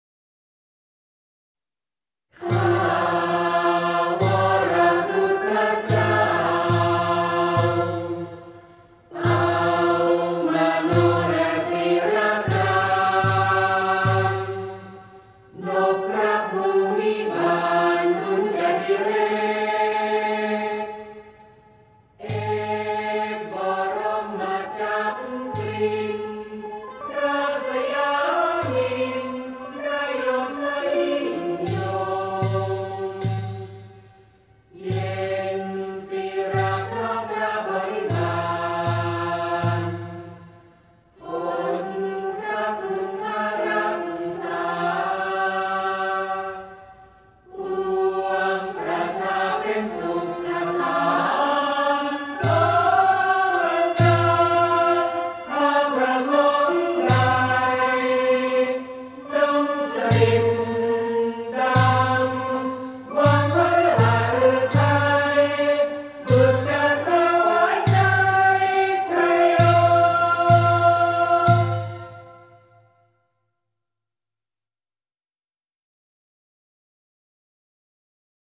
เพลงสรรเสริญพระบารมี (วงมโหรี)
ผู้สร้างสรรค์/ผู้แต่ง/เจ้าของผลงาน : สำนักงานเสริมสร้างเอกลักษณ์ของชาติ